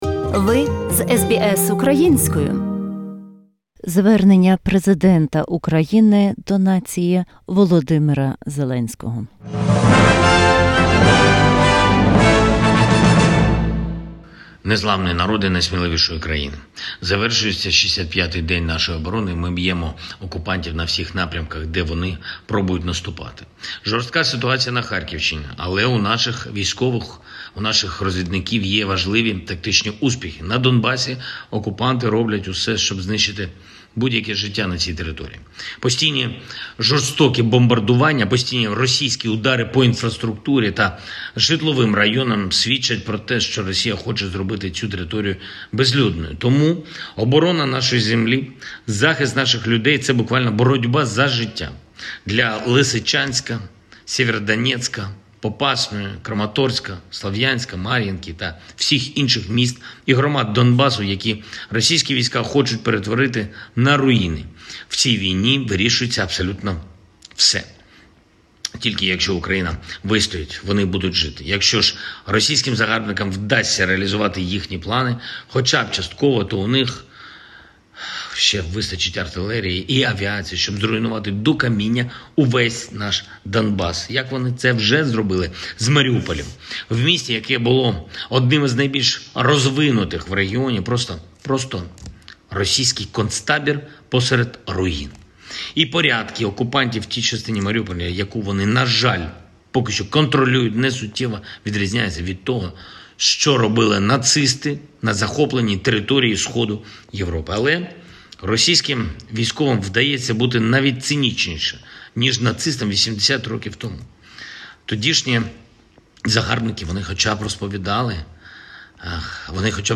Address by President of Ukraine V. Zelenskyy to Ukrainians.